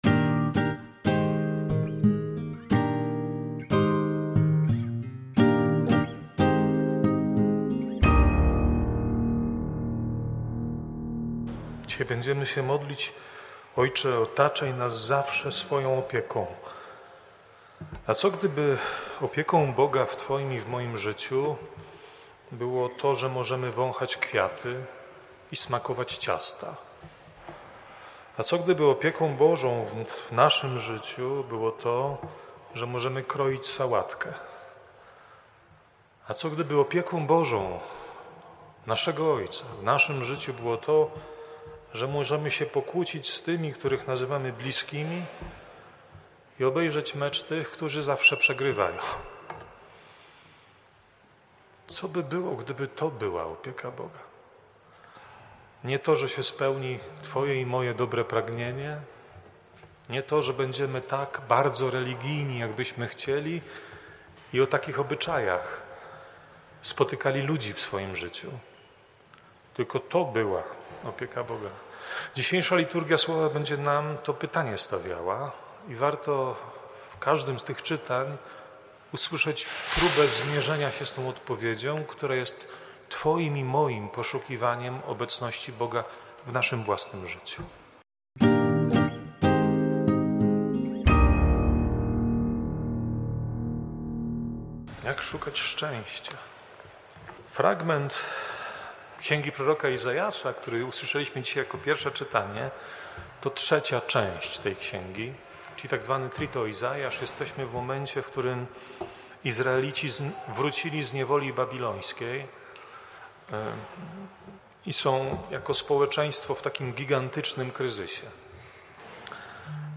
kazania.